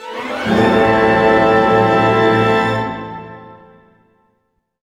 Index of /90_sSampleCDs/Roland - String Master Series/ORC_Orch Gliss/ORC_Minor Gliss